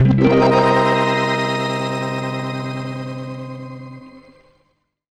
GUITARFX 2-L.wav